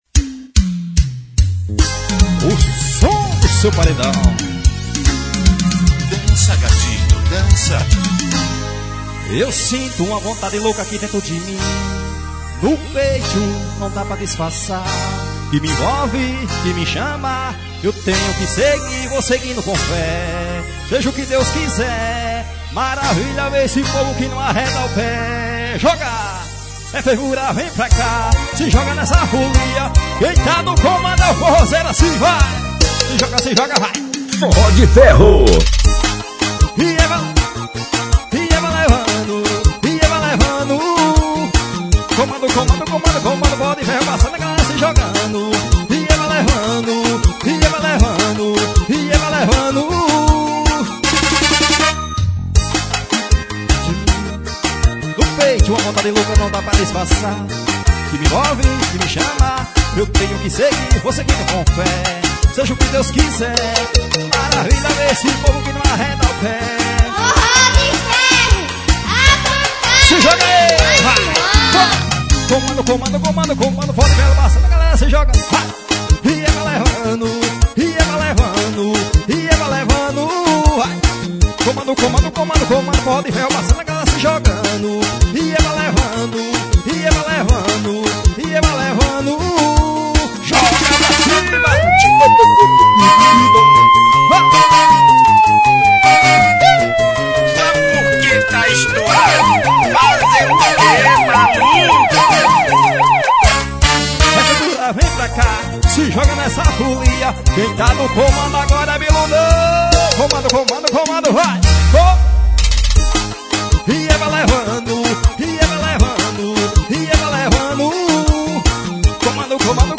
forró pegado.